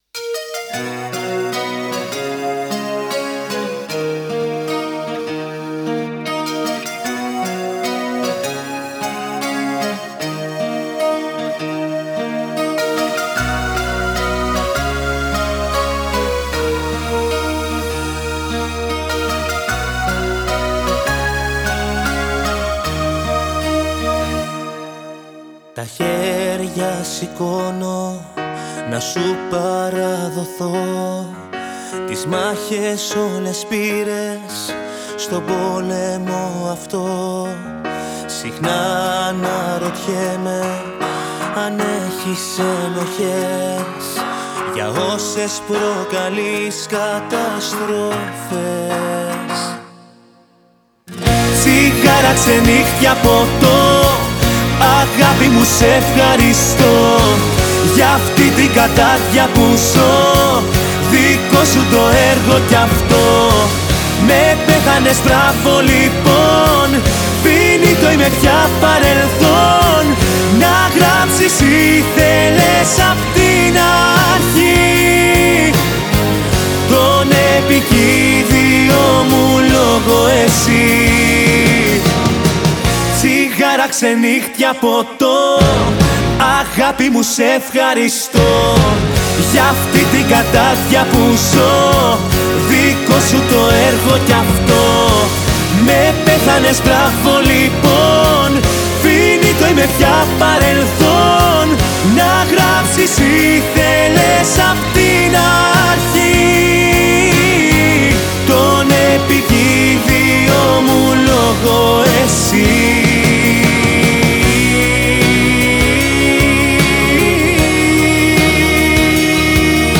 چه صدای دلنشینی داره این خواننده و البته چه ملودی زیبایی .😲